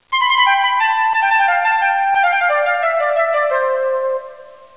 Amiga 8-bit Sampled Voice
1 channel
flute2.mp3